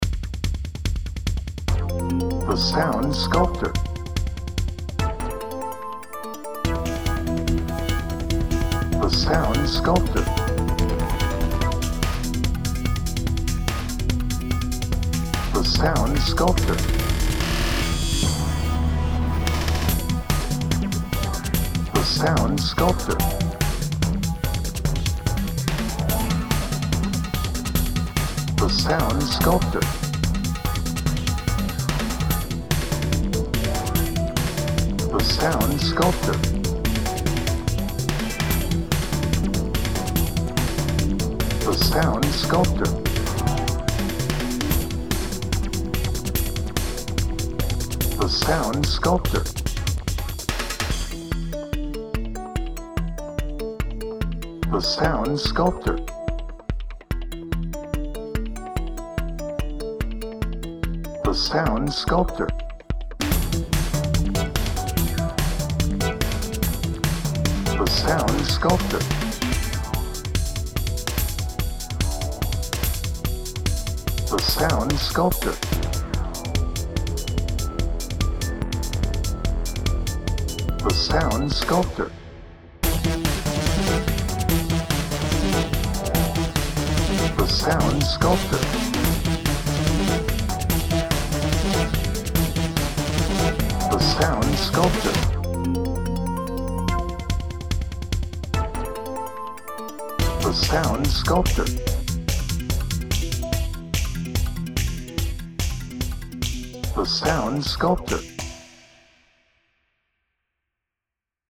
Edgy
Electronic
Tense
Trance